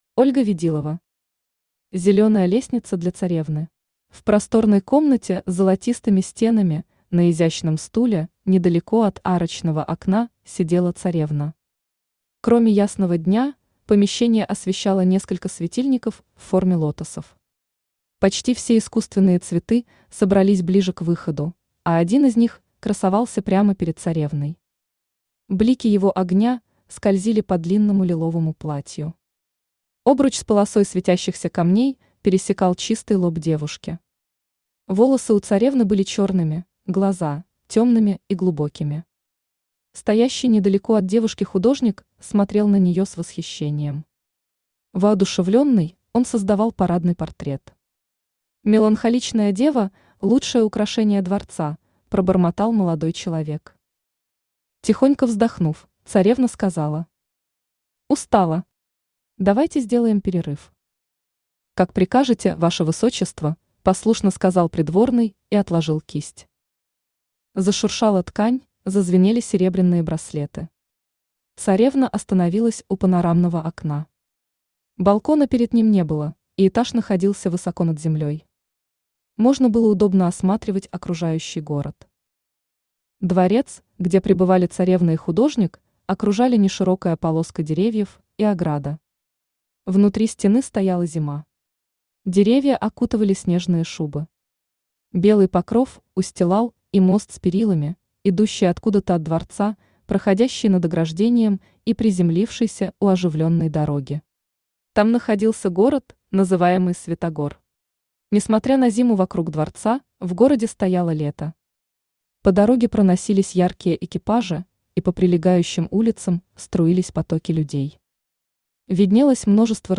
Аудиокнига Зеленая лестница для Царевны | Библиотека аудиокниг
Aудиокнига Зеленая лестница для Царевны Автор Ольга Ведилова Читает аудиокнигу Авточтец ЛитРес.